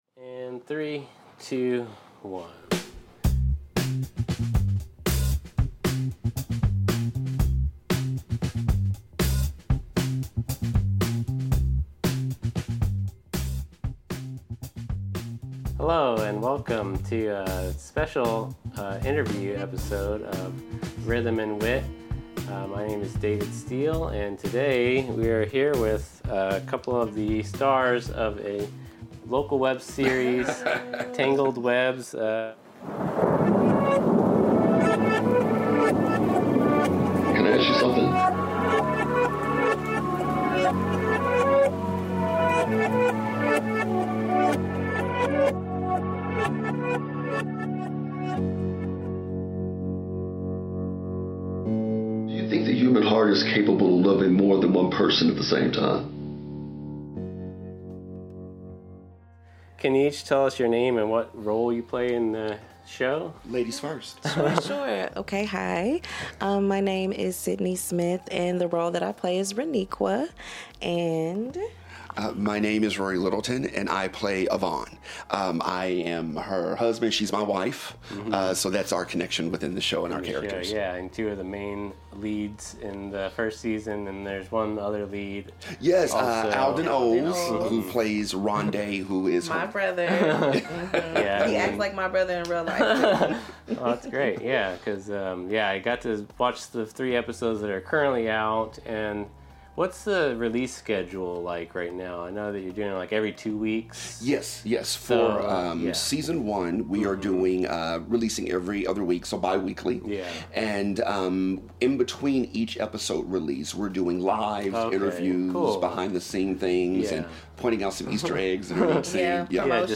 Rhythm & Wit presents a special interview with two of the stars from the web series, "Tangled Webs".